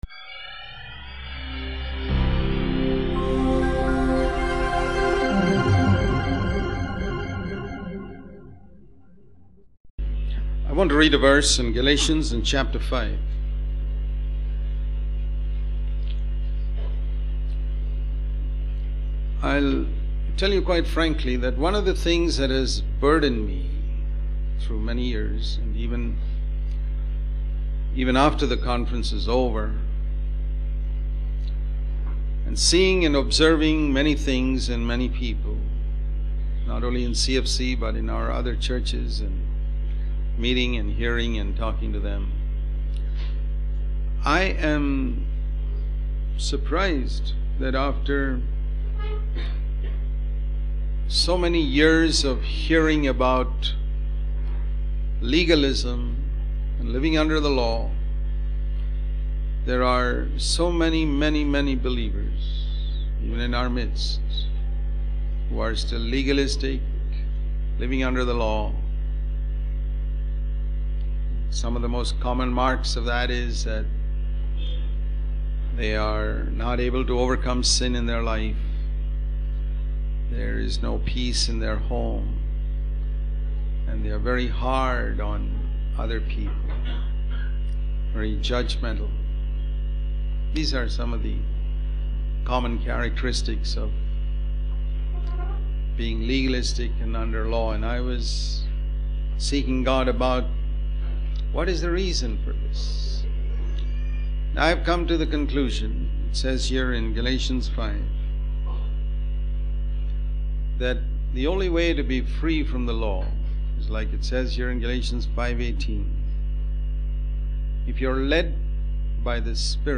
In this sermon, the preacher provides a summary of the book of Romans, highlighting its key themes and teachings. He emphasizes that salvation is by grace and that both worldly and religious people are sinners. The preacher also discusses the concepts of justification by faith, victory over sin, and living in the Holy Spirit.